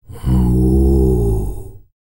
TUVANGROAN04.wav